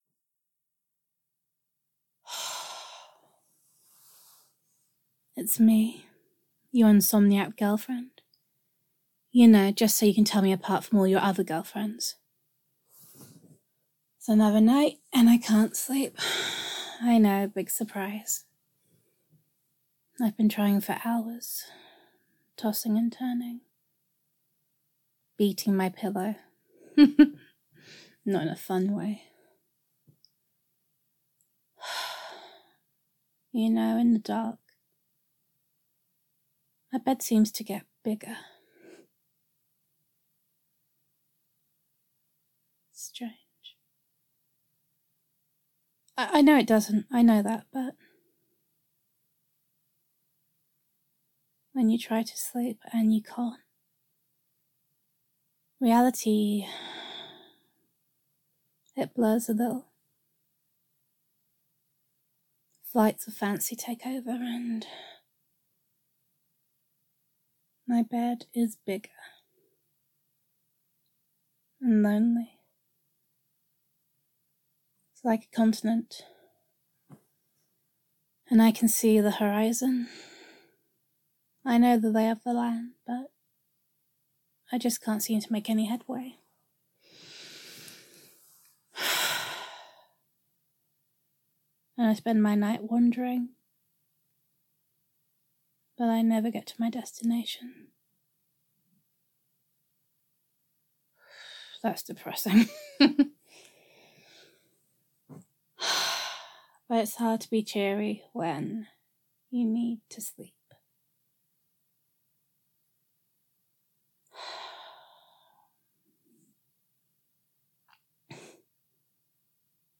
[F4A] Flaming Blush [A Little Bit Ridiculous][Adorable][Insomniac Girlfriend][Gender Neutral][Girlfriend Voicemail]